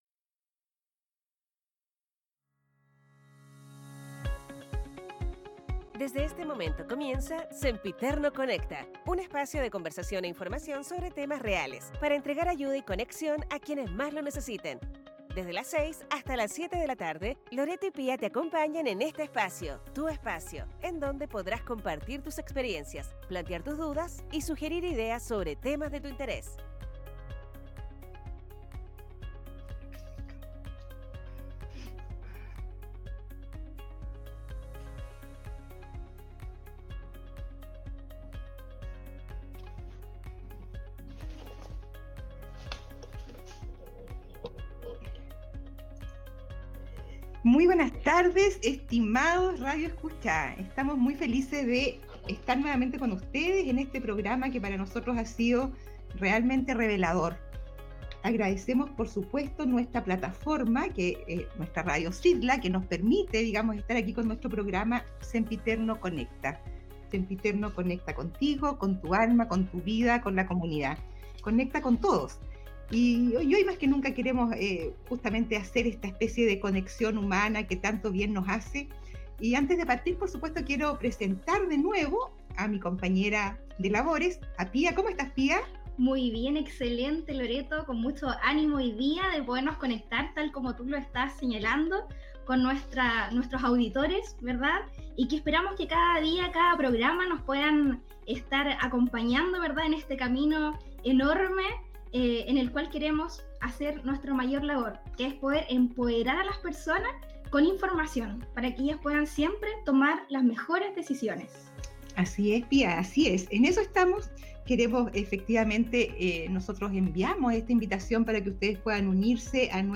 2do capitulo del programa de radio digital: Sempiterno Conecta